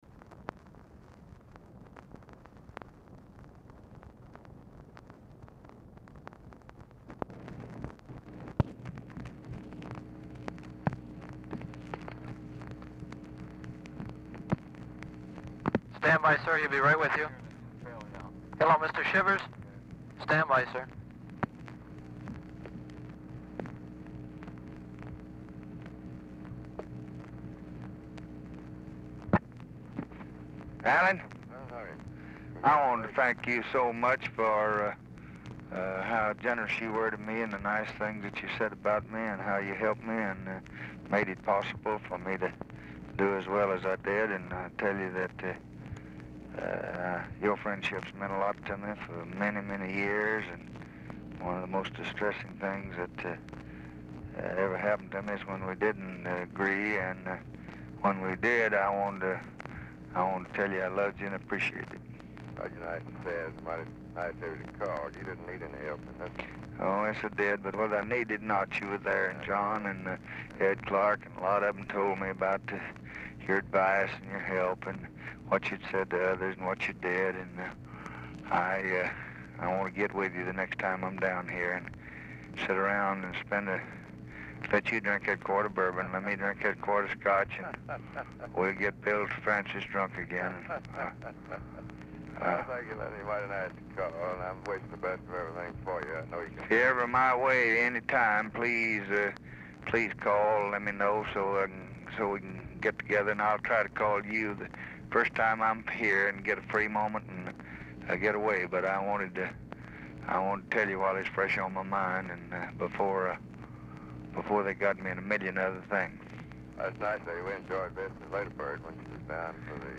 Telephone conversation # 6196, sound recording, LBJ and ALLAN SHIVERS, 11/4/1964, 11:09PM | Discover LBJ
SHIVERS ON HOLD 0:30
Format Dictation belt
Location Of Speaker 1 LBJ Ranch, near Stonewall, Texas